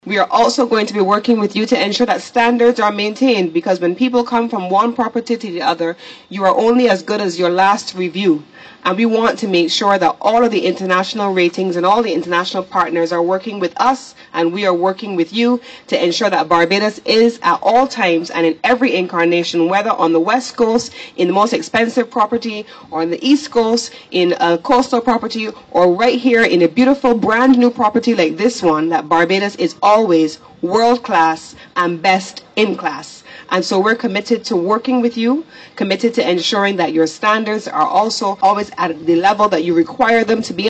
Speaking at the opening of the Bayside Bistro and Nautilus Ocean Suites on Bay Street yesterday, Senator Cummins explained that access to financing will be made available for small hotels to attract investment.
Voice of: Minister of Tourism and International Business Senator Lisa Cummins